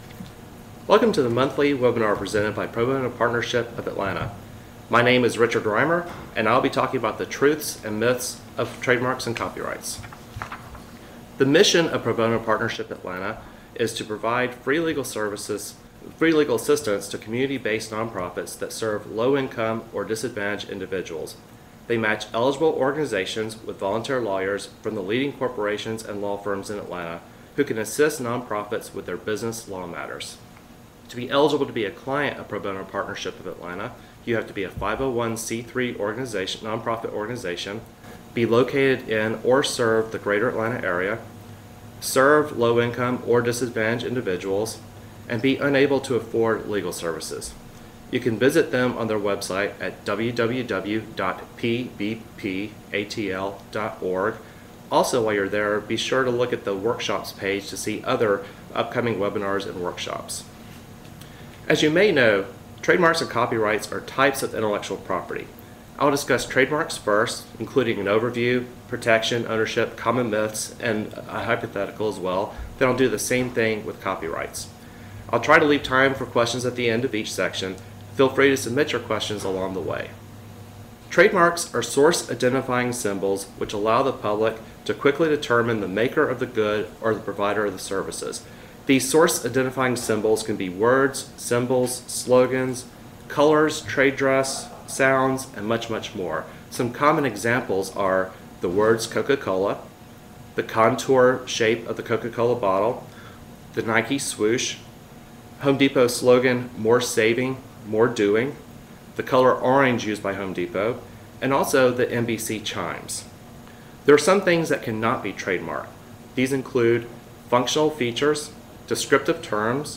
Webcast: Truths & Myths about Trademarks & Copyrights
During this one hour webinar, our speaker helps nonprofits understand: